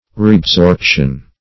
Reabsorption \Re`ab*sorp"tion\ (r[=e]`[a^]b*s[^o]rp"sh[u^]n), n.